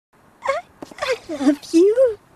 PLAY "I love you~" Yandere Anime Girl
i-love-you-gir.mp3